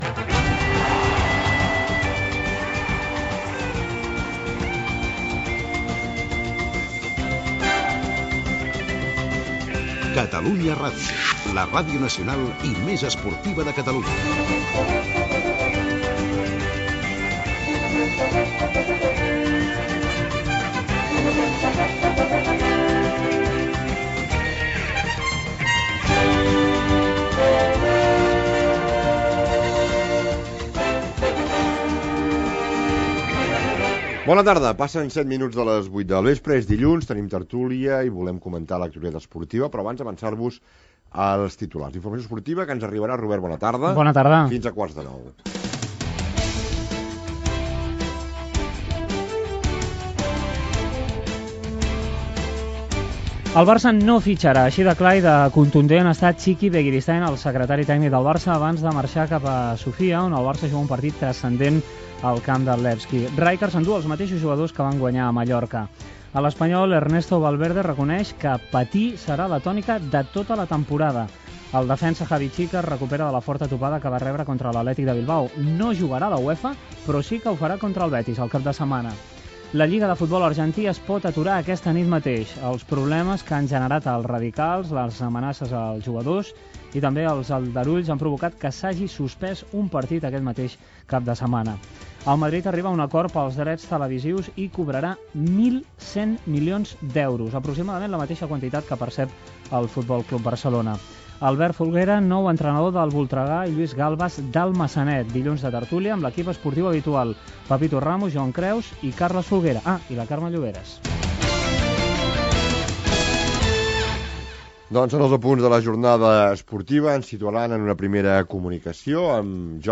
Indicatiu de l'emissora, hora, resum informatiu
Gènere radiofònic Esportiu